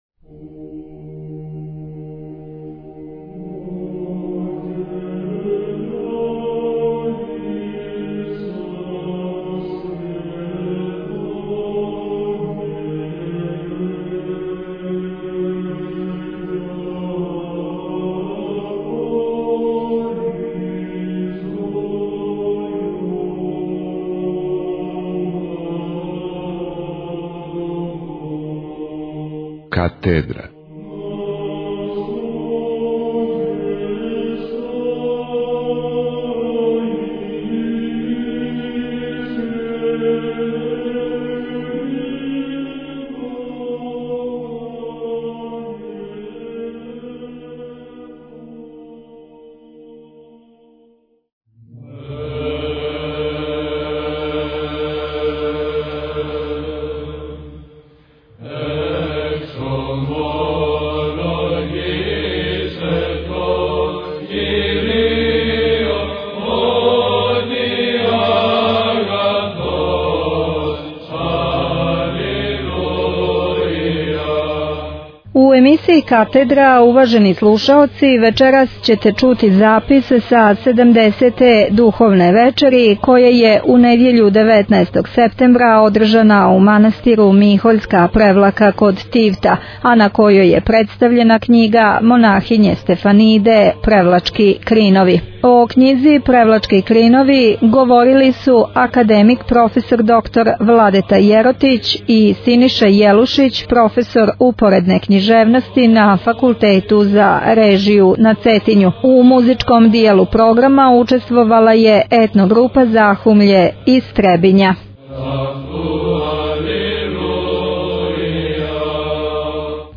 Пјевала је етно - група "Захумље".